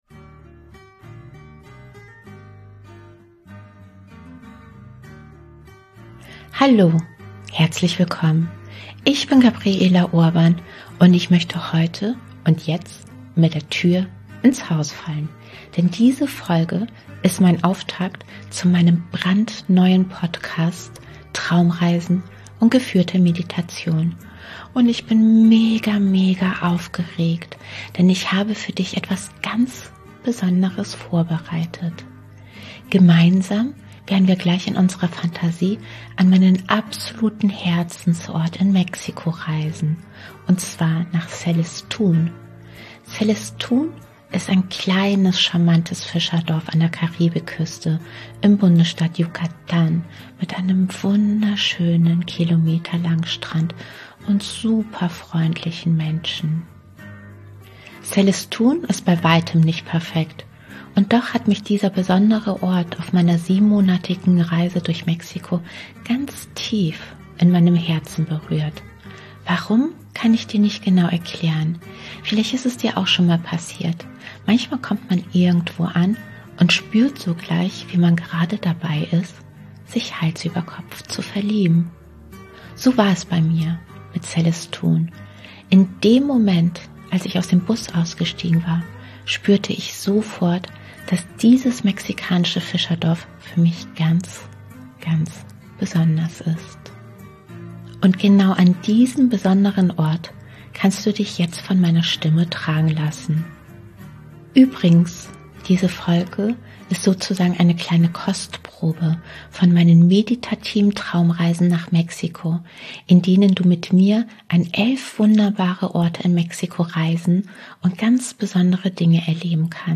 Diese geführte Traumreise ist besonders gut geeignet, wenn dir nach Loslassen, Entspannung und du dir mehr Leichtigkeit und innere Stärke wünscht. Ich wünsche dir ganz viel Freude beim Wegträumen und Meditieren.